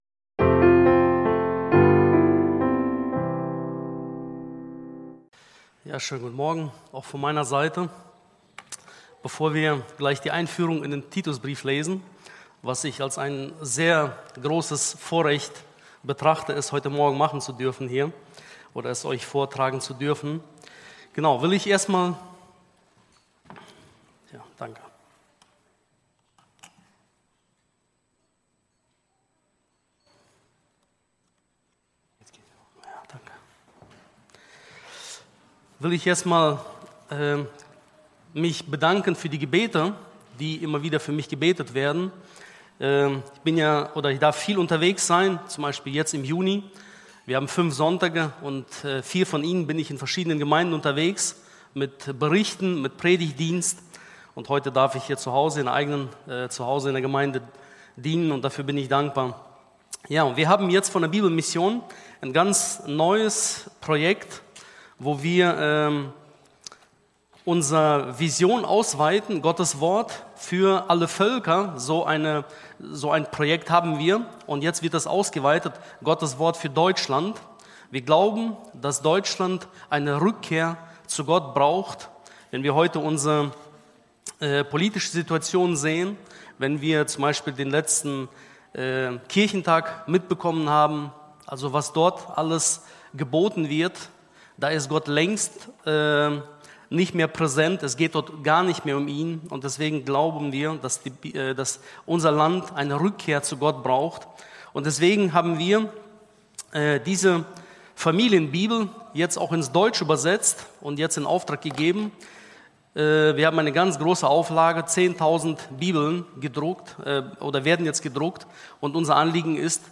Hauptpredigt